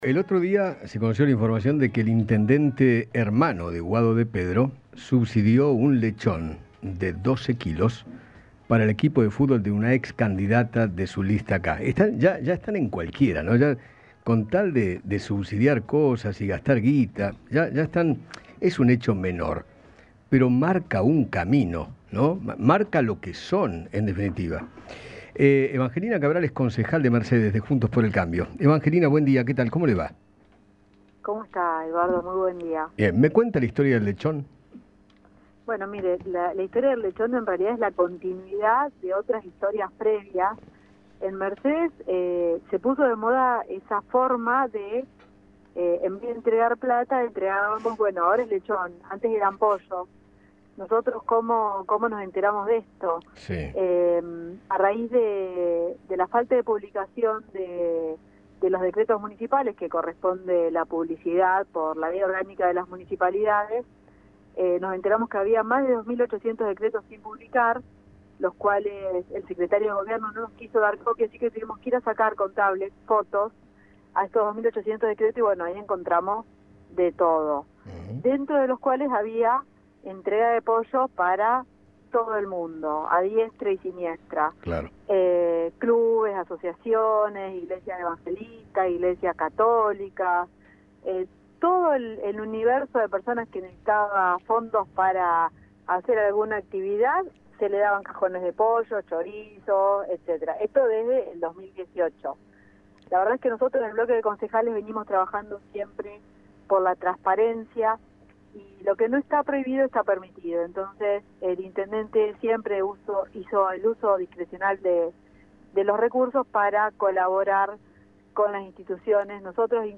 Eduardo Feinmann habló con la concejal de Mercedes, Evangelina Cabral, sobre lo sucedido en aquella localidad con el hermano del ministro del interior, quien subsidió un lechón de 12 kilos para el club de una ex candidata de su lista k.